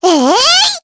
Luna-Vox_Attack4_jp.wav